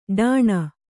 ♪ ḍāṇa